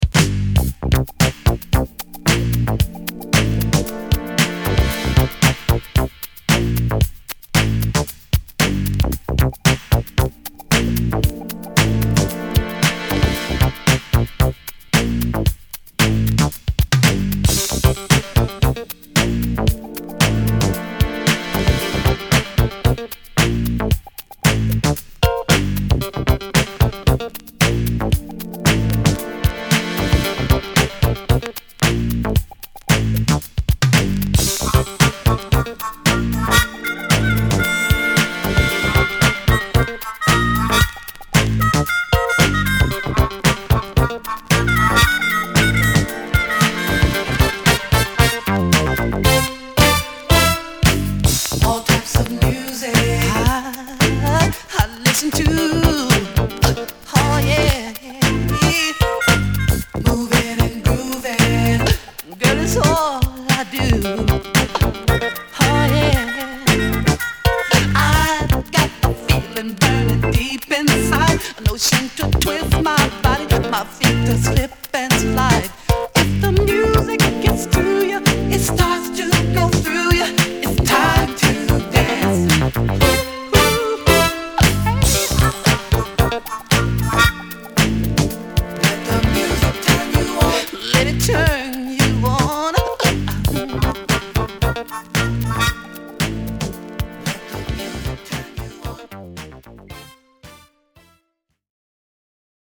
ホーム DISCO 80's 12' C